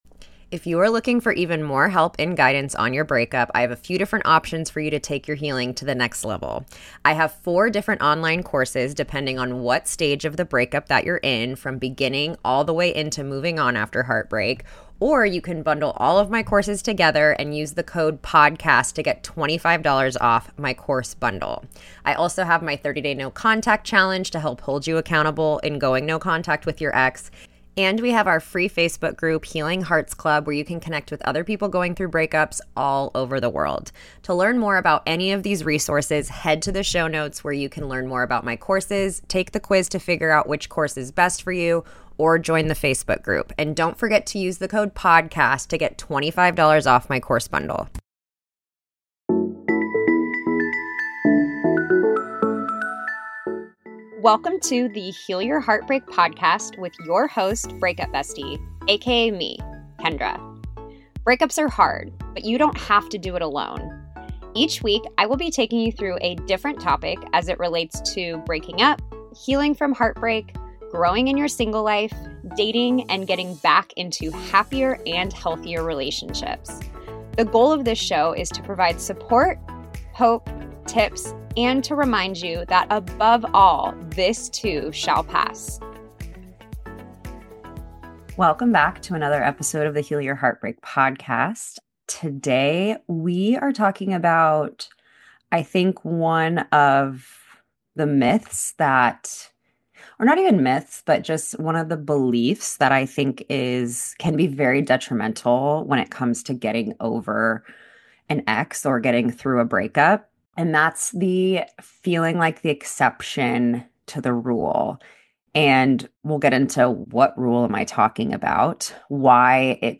In today's solo episode I'm talking about the myth that I believe keeps so many people connected to their exes which is, being the "exception to the rule". We talk about in what ways people believe they're the exceptions to the rule, the issues that it causes, why we want to believe we're the exception, and what to do about it.